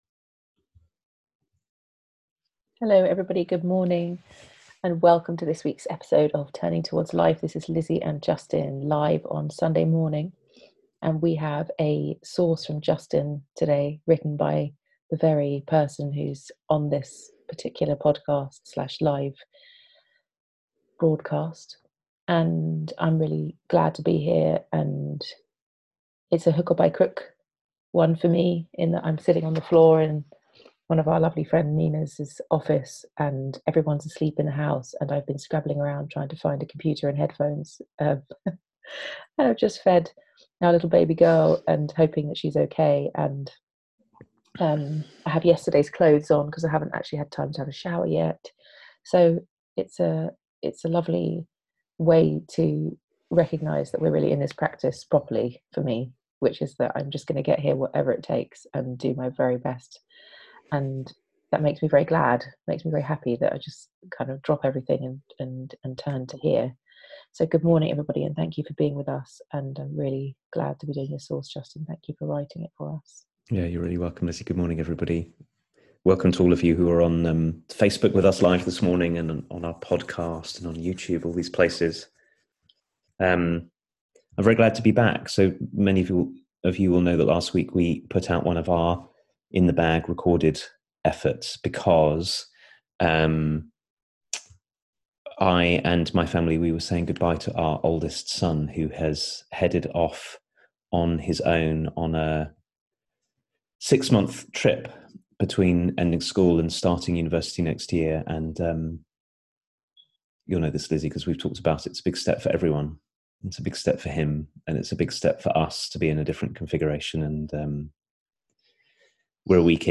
A conversation about the ways we exile aspects of ourselves, and the possibility of taking the wholeness of ourselves into account